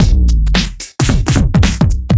OTG_DuoSwingMixA_110a.wav